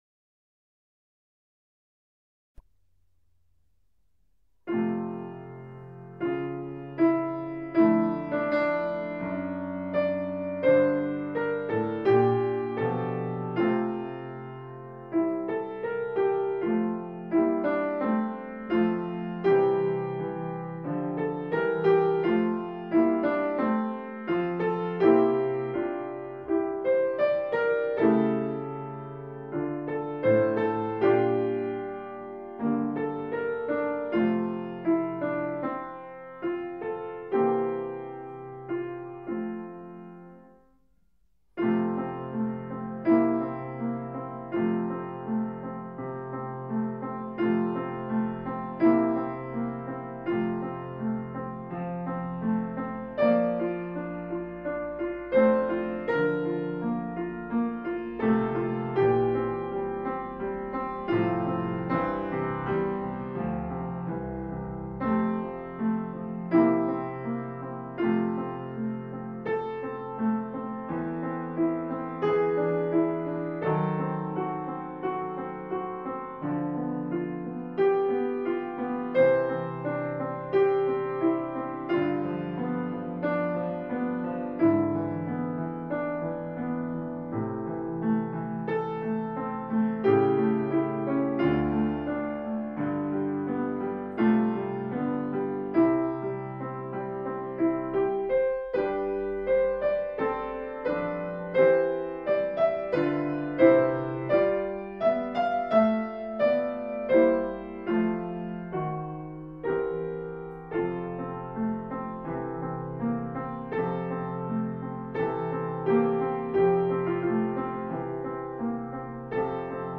Panis base piano
Panis-Angelicus-KARAOKE-PIANO-ACCOMPANIMENT-F-Major-Franck.mp3